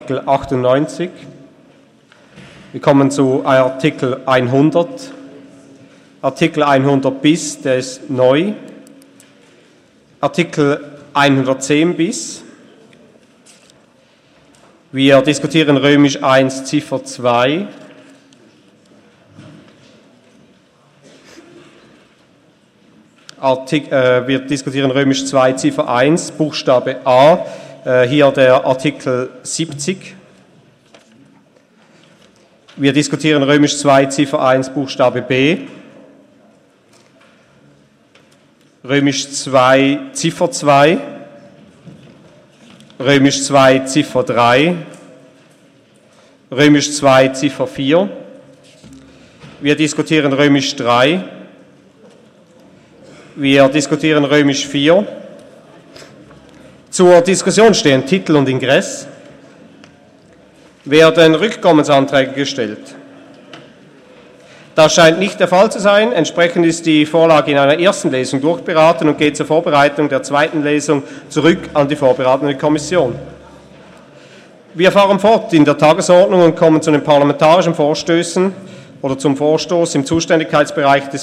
Session des Kantonsrates vom 23. und 24. April 2018